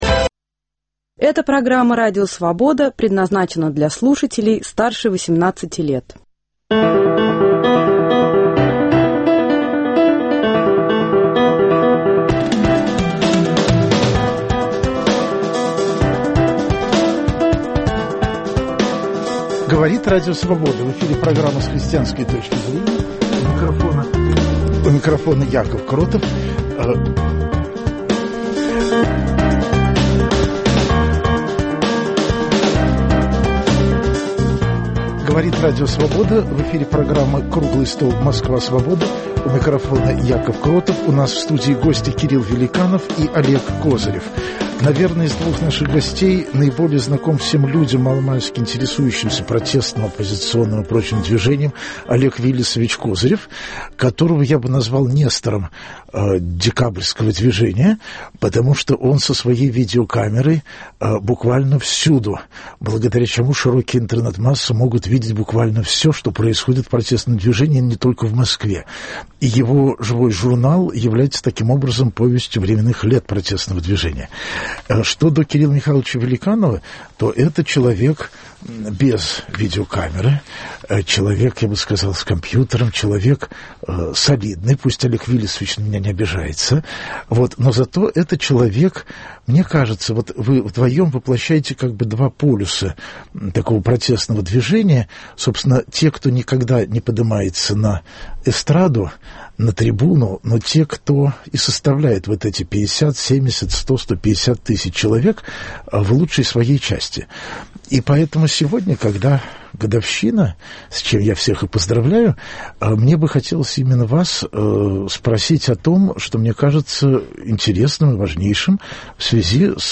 Круглый стол: Москва Свободы